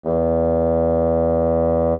bassoon_E2_mf.mp3